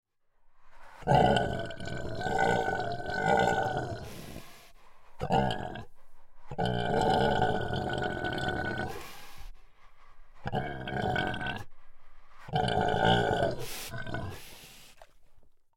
Звуки свиньи
Звук свиного дыхания